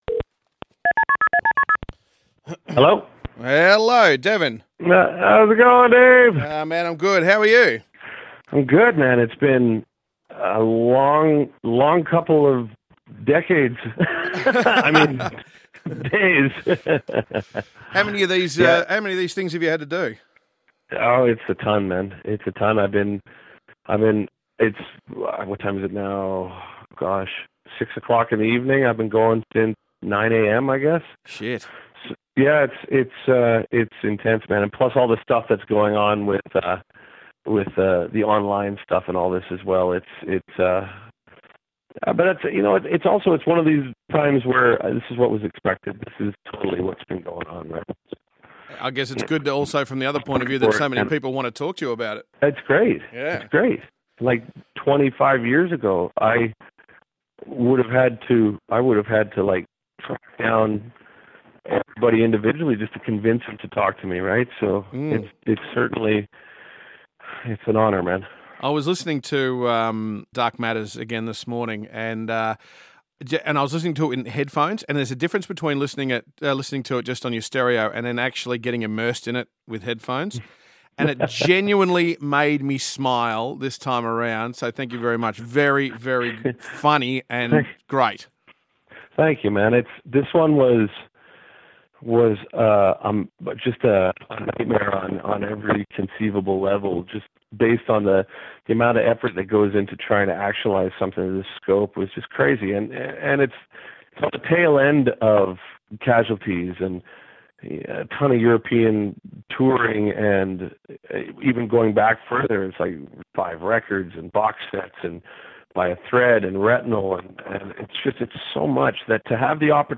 Devin Townsend interview